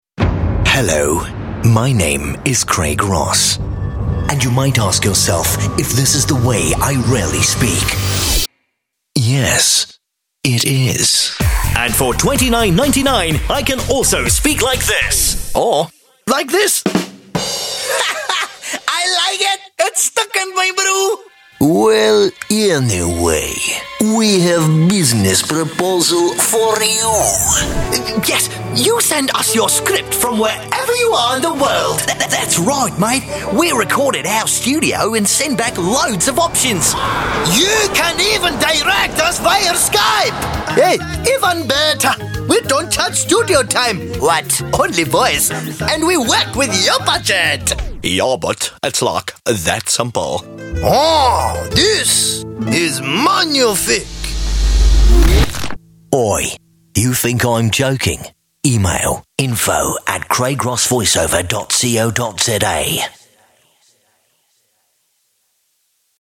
britisch
Sprechprobe: Sonstiges (Muttersprache):
Super Diverse - Can put on and hold almost any accent and add character to any product.